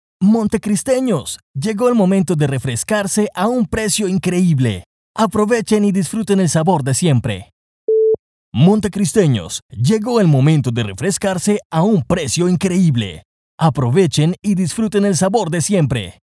Male
Spanish (Latin American), English (Latin American Accent)
Radio / TV Imaging
Drink Product Spot